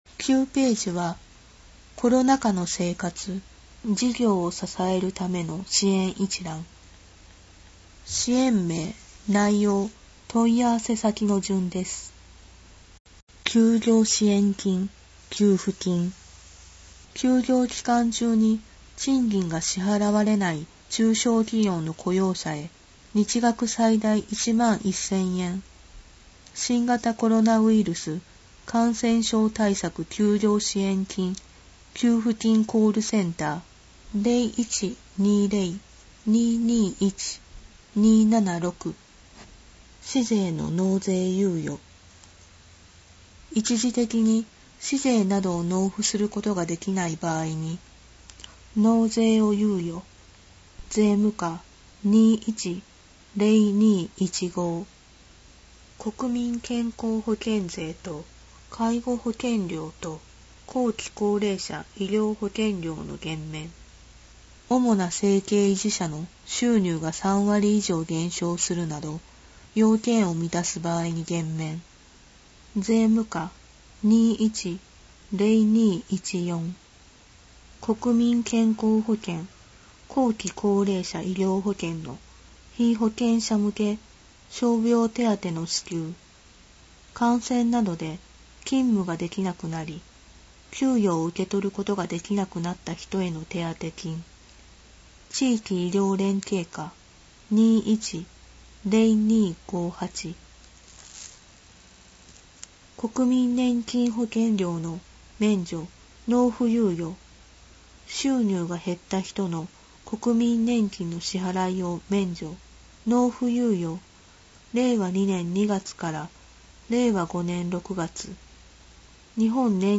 声の広報　広報たかはし8月号（214）